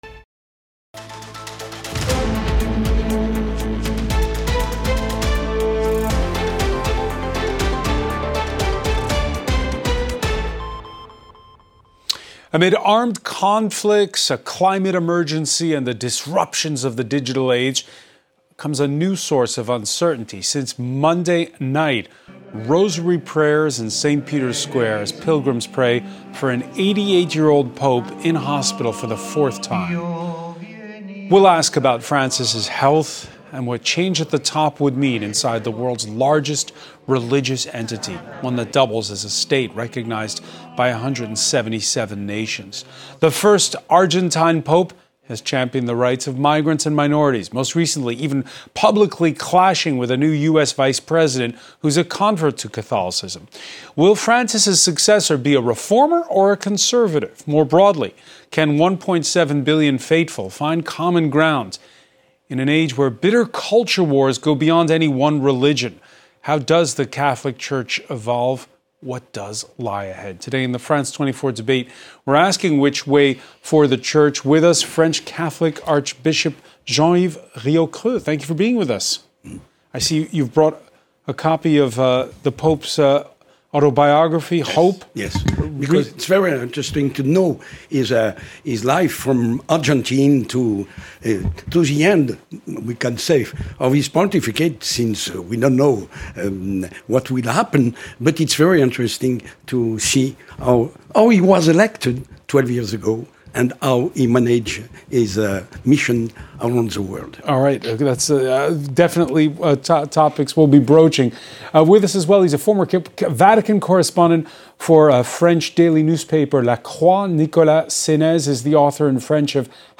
A live debate on the topic of the day, with four guests.